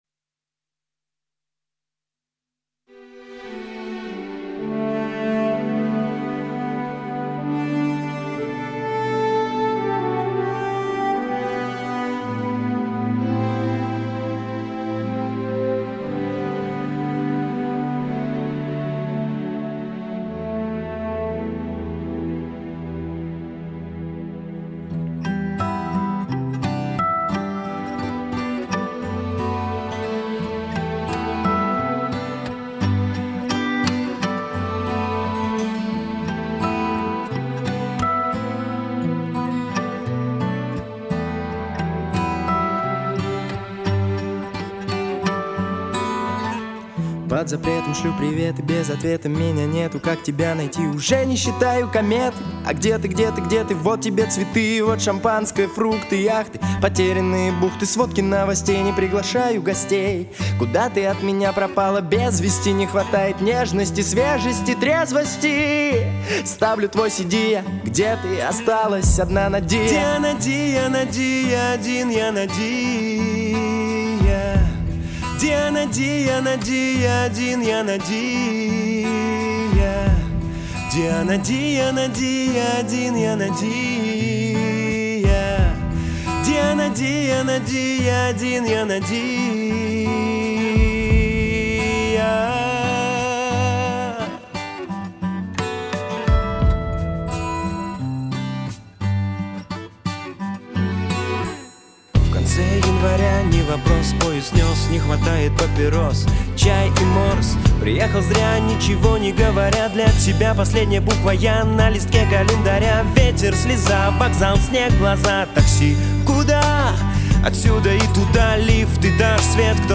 хорошего качества.